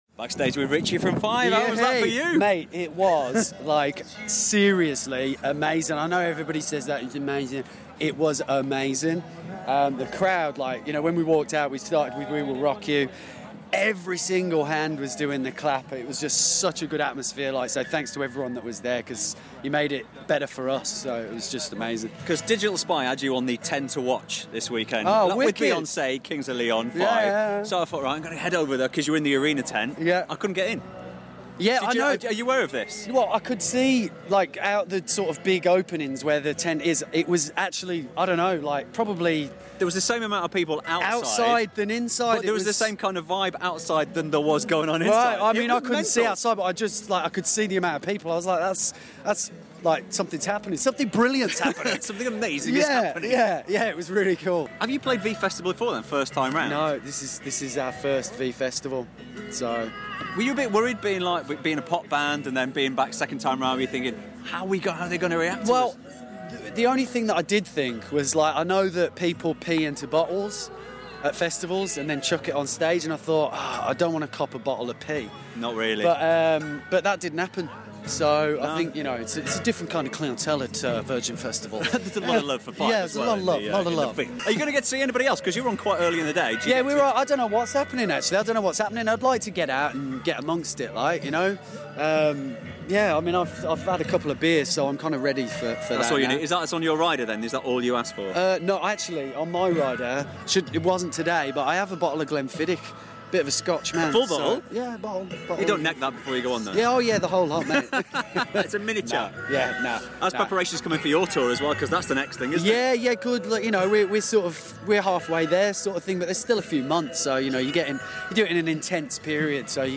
Richie from 5ive @ V Festival 2013